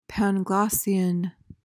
PRONUNCIATION:
(pan-GLOS-ee-uhn)